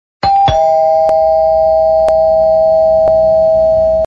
Description: Ting tong door bell